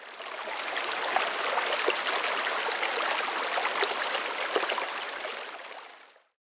fountain1.wav